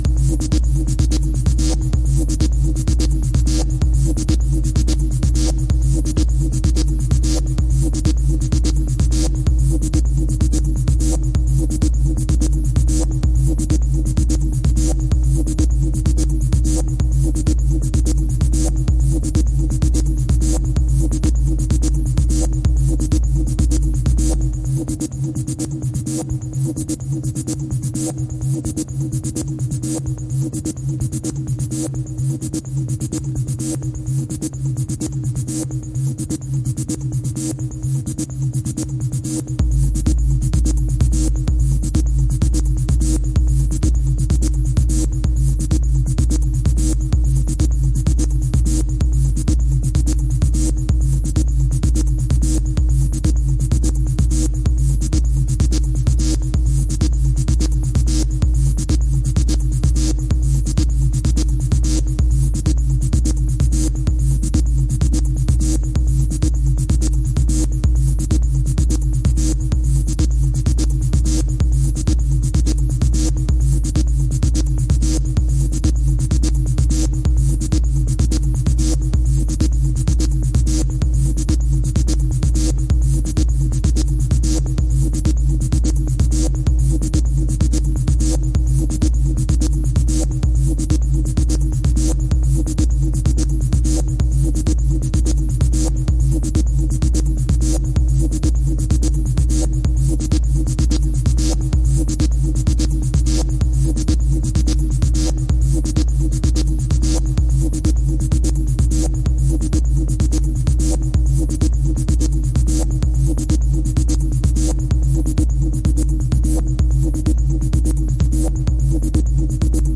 ほぼアナログ機材、ライブ録音で制作される実験ミニマル作品。